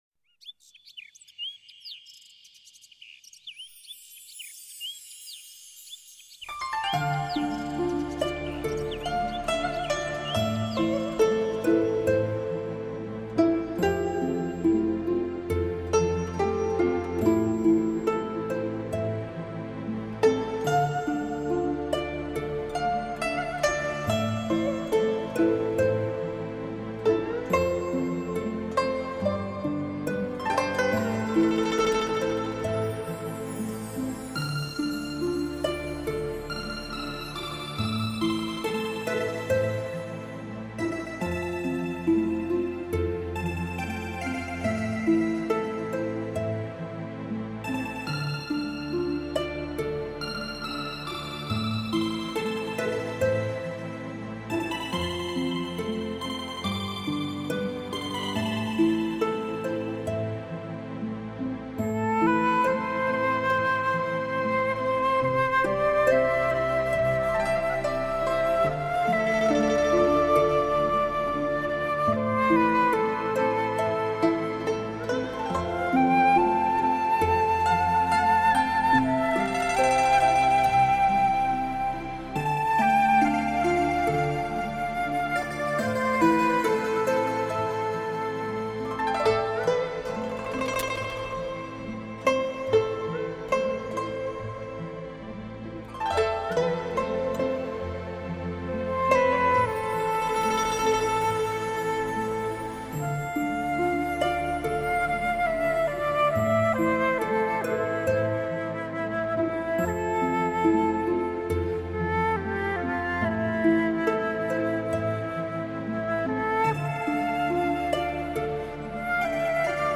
无限宁静  无限意境  无限飘渺